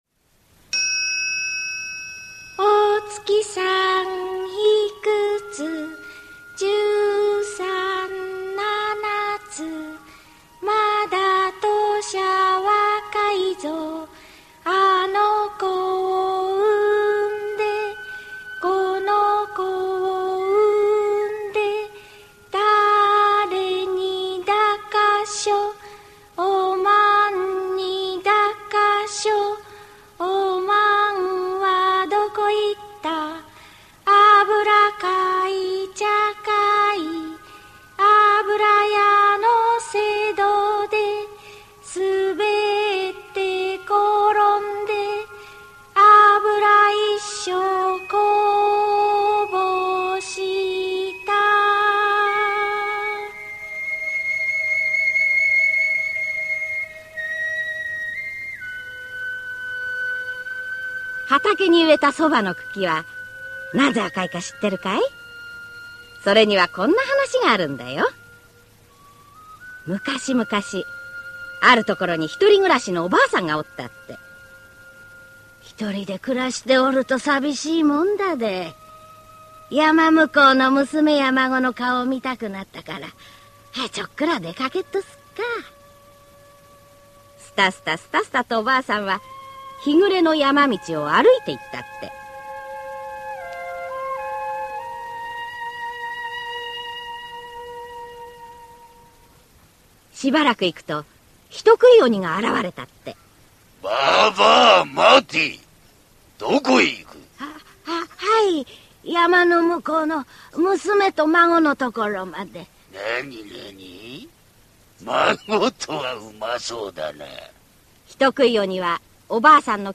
[オーディオブック] そばのくきはなぜあかい